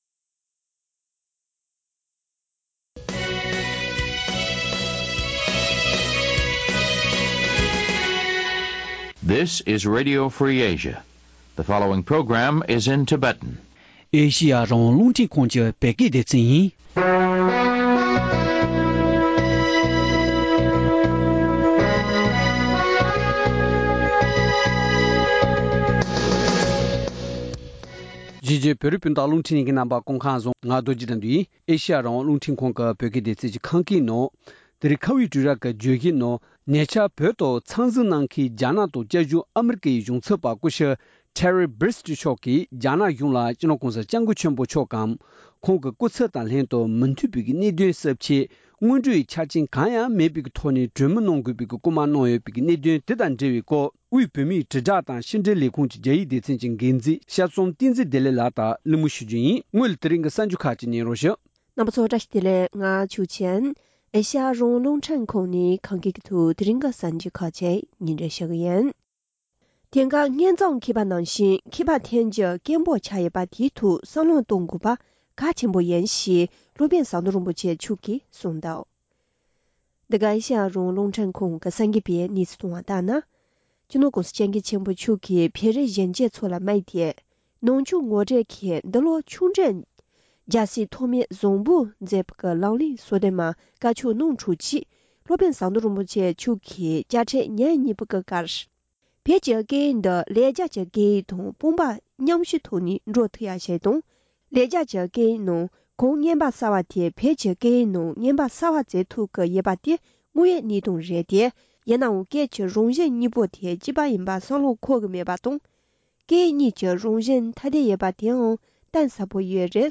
རྒྱ་ནག་ཏུ་བཅའ་བཞུགས་ཨ་རིའི་གཞུང་ཚབ་མཆོག་བོད་ནང་བོད་པའི་ཐད་གླེང་མོལ།